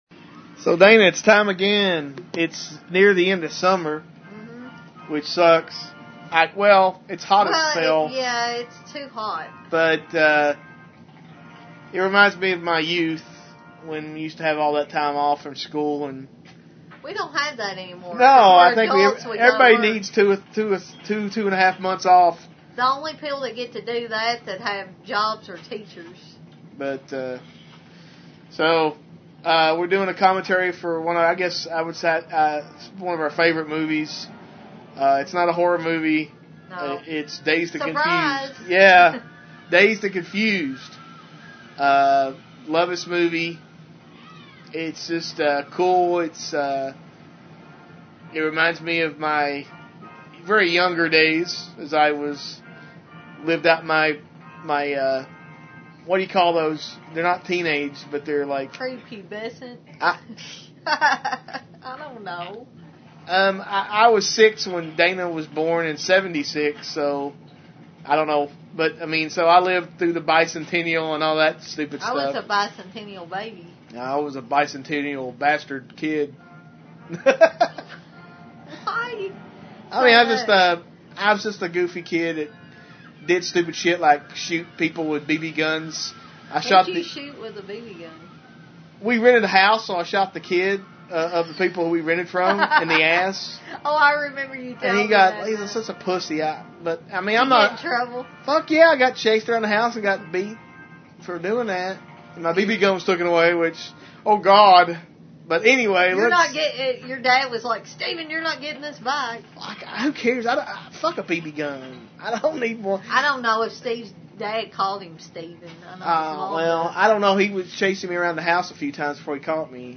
DOWNLOAD FILE HERE 25.4 MB RIGHT CLICK AND SAVE TARGET AS DOWNLOAD IN STEREO 55.4 MB RIGHT CLICK AND SAVE TARGET AS